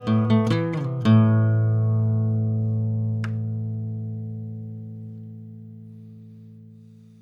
Genre: Folk
Tags: acoustic guitar , Americana , flamenco , dramatic